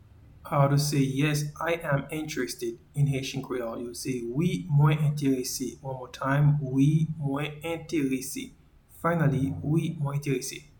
Pronunciation and Transcript:
Yes-I-am-interested-in-Haitian-Creole-Wi-mwen-enterese.mp3